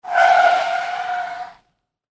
Звук визга шин об асфальт при резком торможении